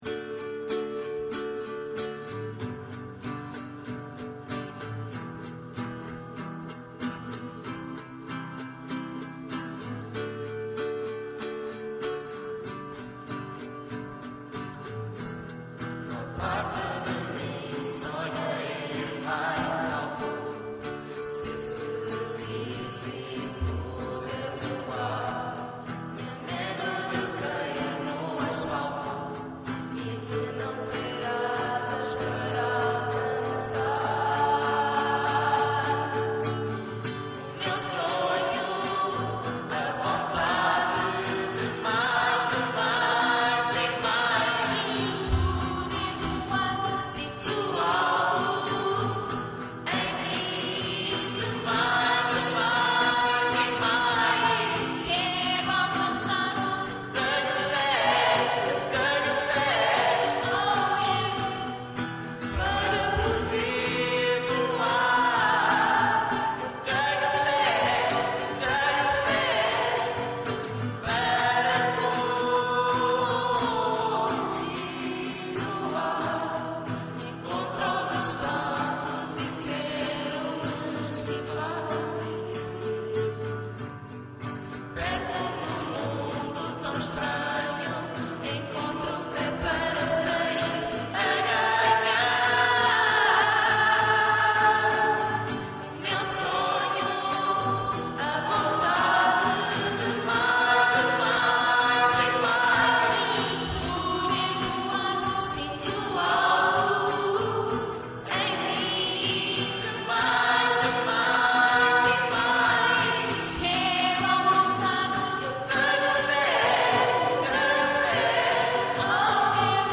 O Festival realizou-se no passado dia 18 de Maio (Sábado) no Salão Paroquial da Igreja João Baptista Scalabrini (Igreja Nova de Amora)
Cada paróquia (da vigararia/concelho do Seixal) apresenta até 2 músicas cristãs sobre o tema do festival.